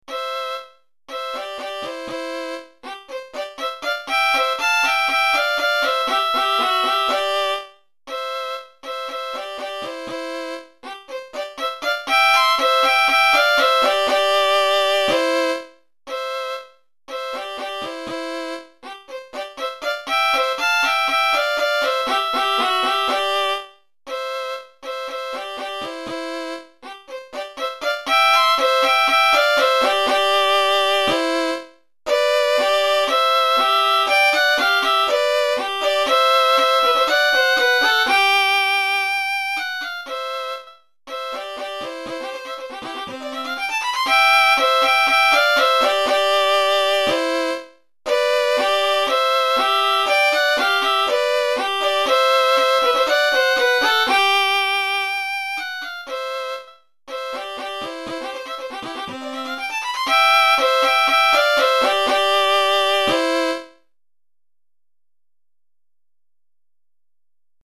Répertoire pour Violon - 2 Violons